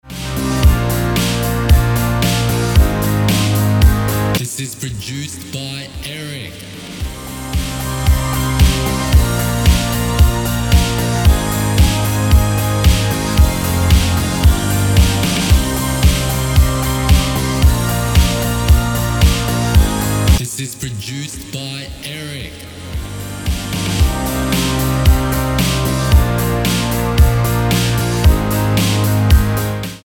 An absolutely timeless-sounding masterpiece.
Key: C minor Tempo: 113BPM Time: 4/4 Length: 3:07